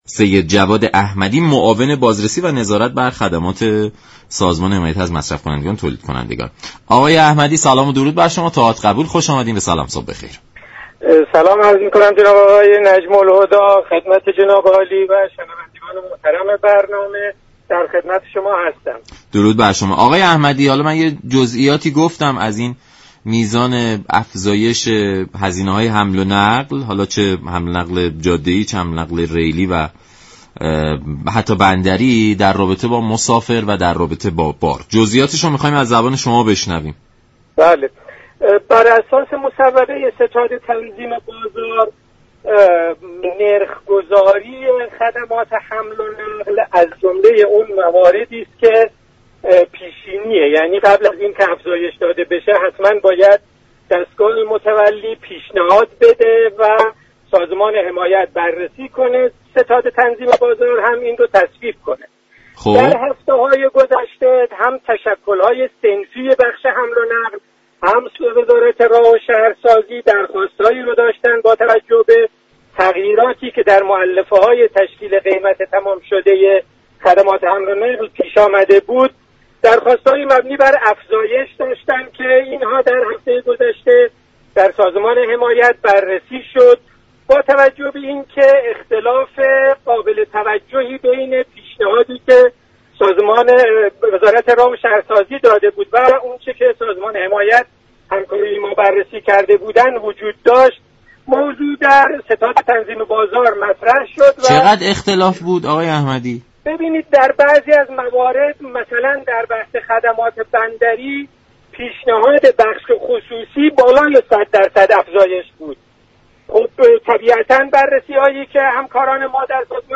به گزارش شبكه رادیویی ایران، سید جواد احمدی معاون نظارت و بازرسی بر خدمات سازمان حمایت از مصرف كنندگان و تولید كنندگان در برنامه «سلام صبح بخیر» از افزایش نرخ جابه‌جایی مسافر و حمل كالا در ناوگان ریلی و جاده‌ای خبر داد و گفت: به دلیل تغییراتی كه در مولفه های تشكیل قیمت تمام شده خدمات حمل و نقل پیش آمده، تشكل های صنفی بخش حمل و نقل و وزارت راه و شهرسازی طی هفته های اخیر، درخواست هایی را برای افزایش قیمت داشته اند.